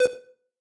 synth3_15.ogg